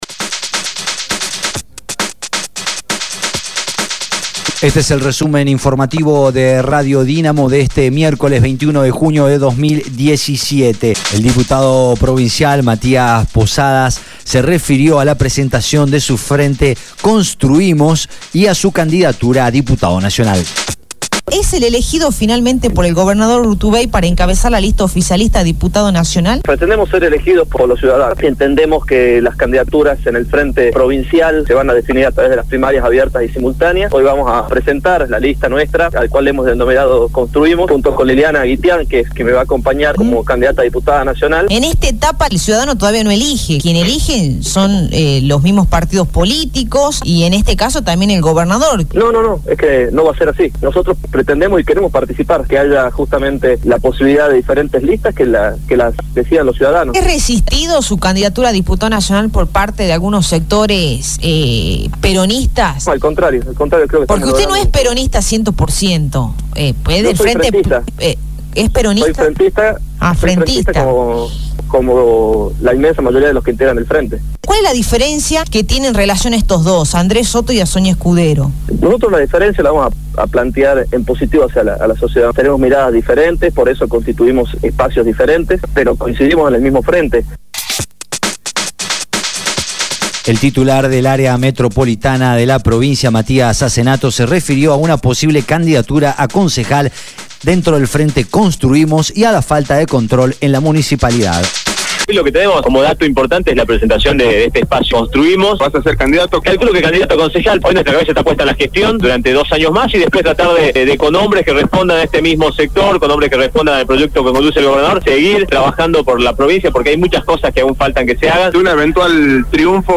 Resumen Informativo de Radio Dinamo del día 21/06/2017 2° Edición
El diputado provincial Matías Posadas se refirió a la presentación de su frente Construimos y a su candidatura como diputado nacional.
El diputado provincial del Partido Obrero, Julio Quintana se refirió a la lista que llevará el frente de izquierda junto al PTS en la provincia de Salta.